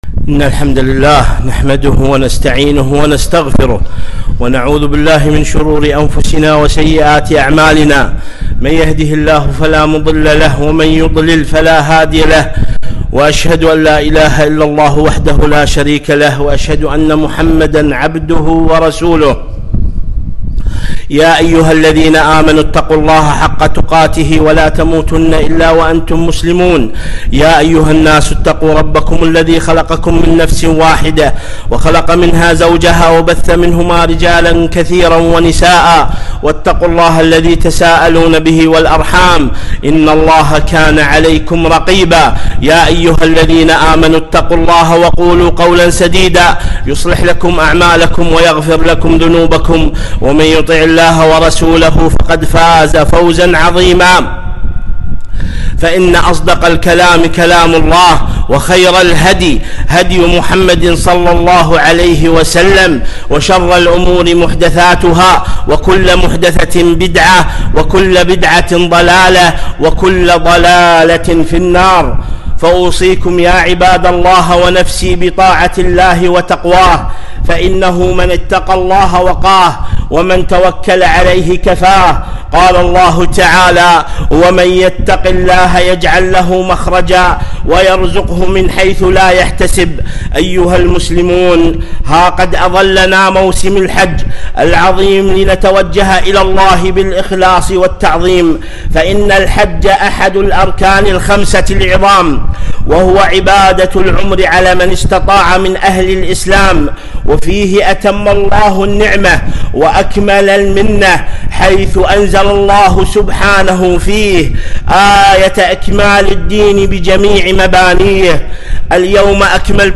خطبة - (وأذن في الناس بالحج)